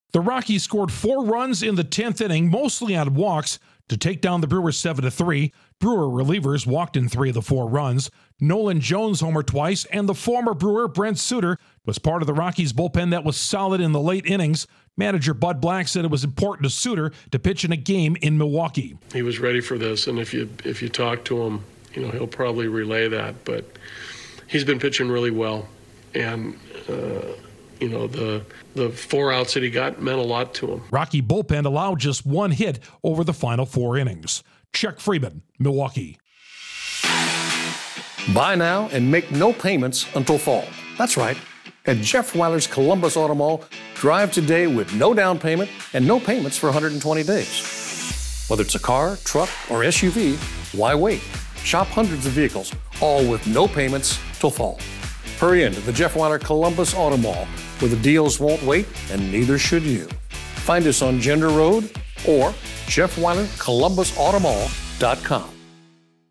The Rockies take advantage of Milwaukee wildness to earn an extra-inning win. Correspondent